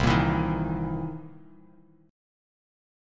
C7#9 chord